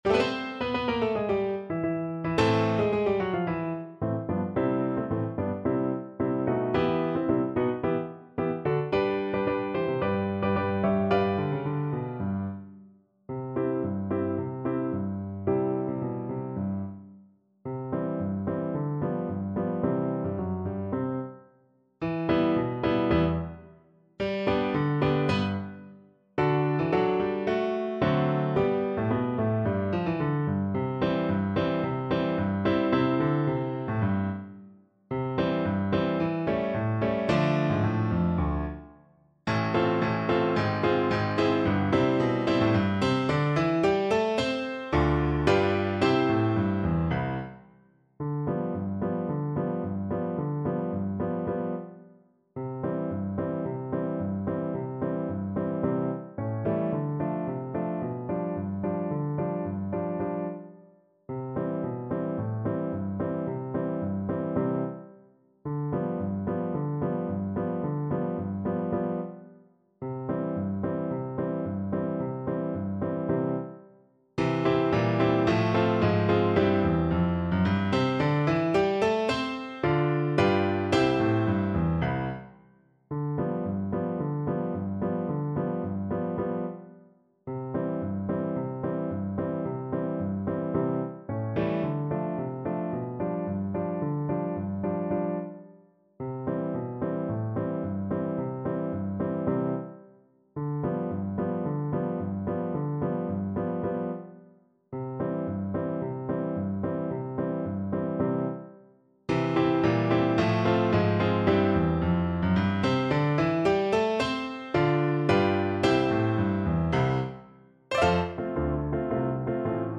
Play (or use space bar on your keyboard) Pause Music Playalong - Piano Accompaniment Playalong Band Accompaniment not yet available transpose reset tempo print settings full screen
2/2 (View more 2/2 Music)
C major (Sounding Pitch) (View more C major Music for Flute )
Classical (View more Classical Flute Music)